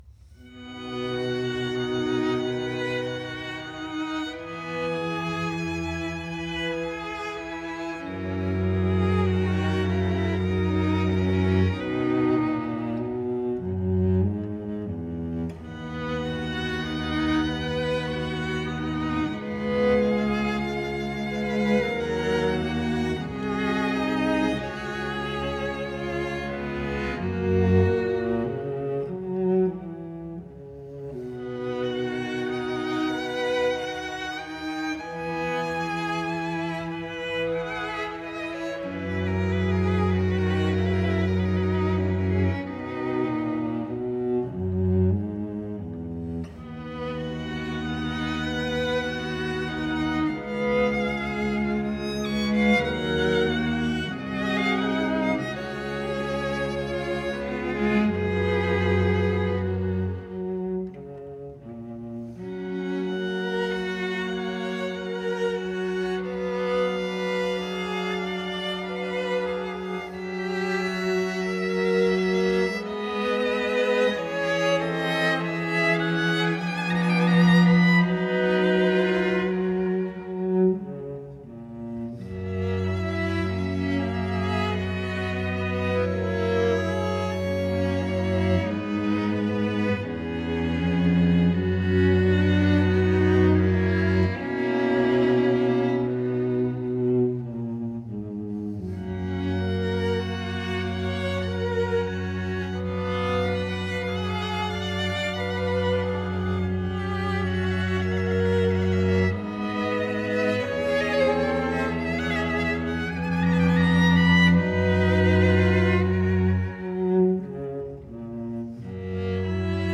В нём действительно можно услышать и почерпнуть то утешение, которое гайдновское творчество даёт человеку.